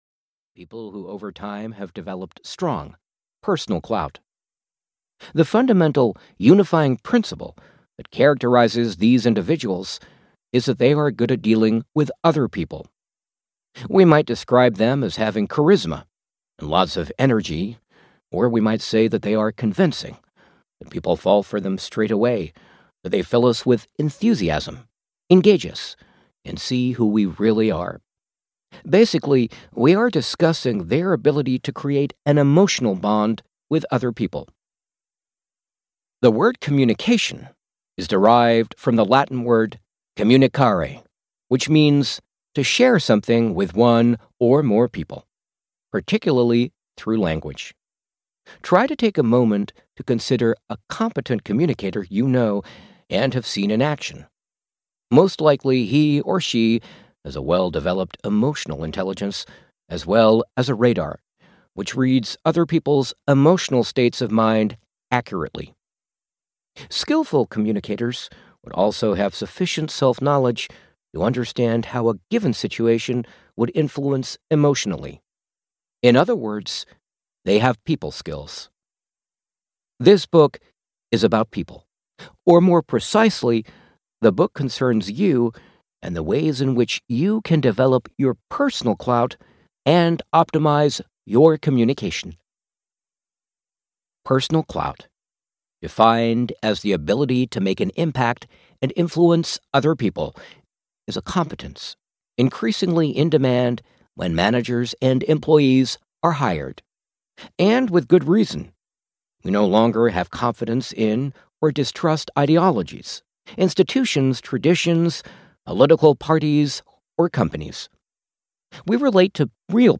Clout (EN) audiokniha
Ukázka z knihy